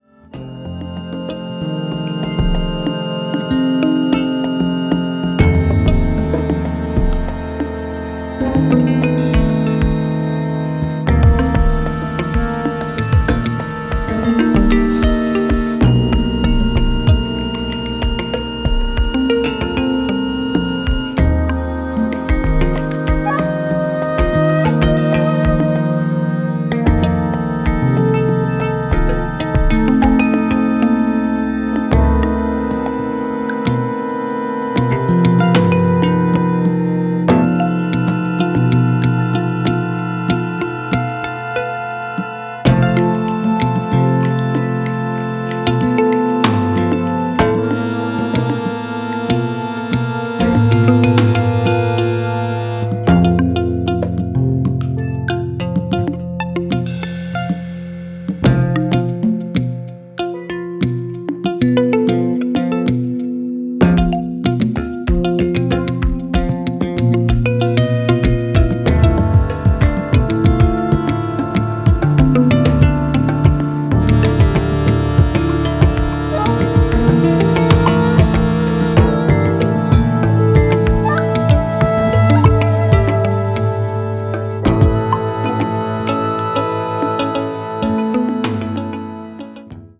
おおよそ95BPM程のゆったりとしたテンポで、パーカッションのポリリズミカルなフレーズ
ニューエイジ・オブ・ニューエイジな風情も感じられるミニマル・アンビエント音風景